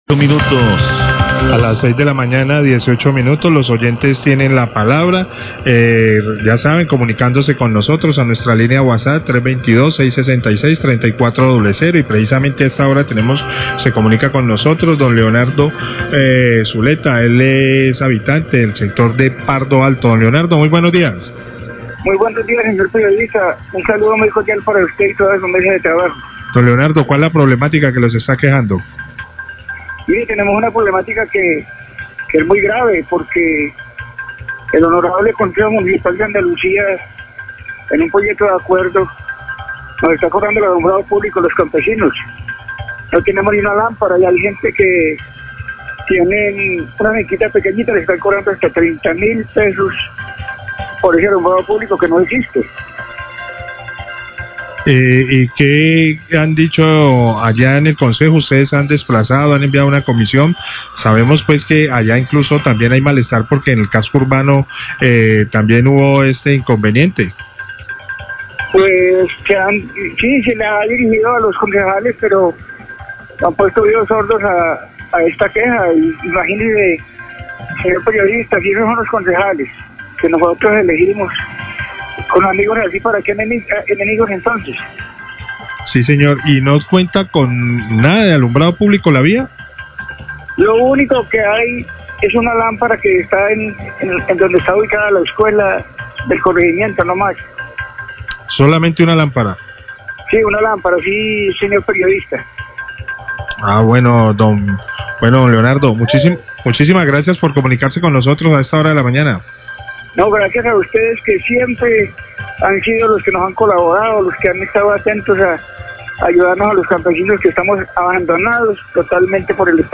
Radio
queja oyente